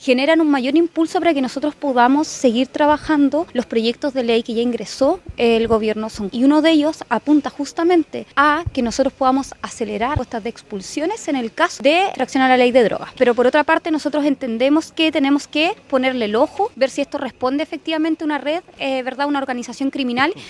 La Delegada Regional de Los Lagos, Giovanna Moreira, afirmó que estos antecedentes impulsan la necesidad de acelerar los procesos de expulsión.